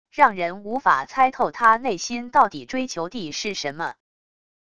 让人无法猜透他内心到底追求地是什么wav音频生成系统WAV Audio Player